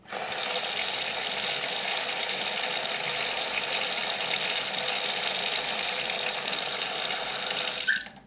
Abrir el grifo de un baño
Sonidos: Agua
Sonidos: Hogar